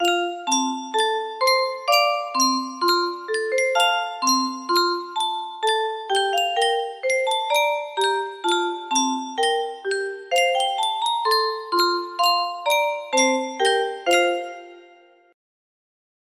Yunsheng Music Box - Children's Prayer Y349 music box melody
Full range 60